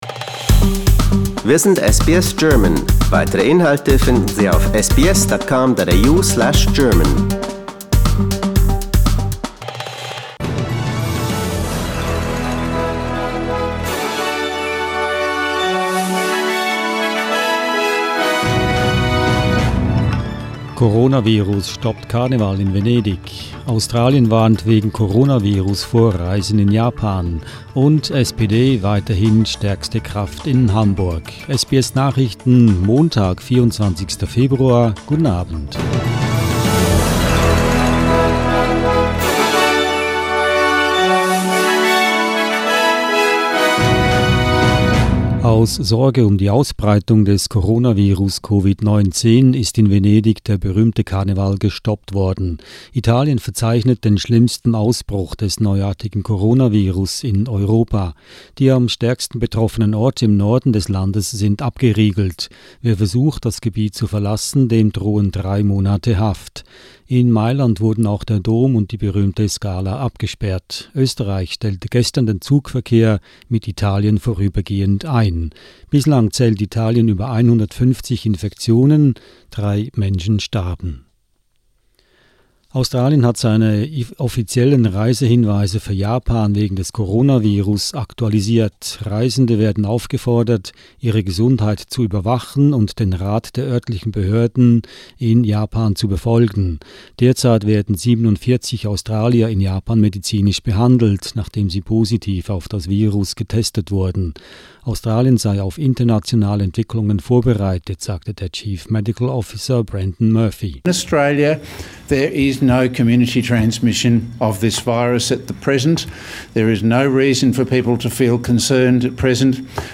SBS Nachrichten, Montag 24.02.20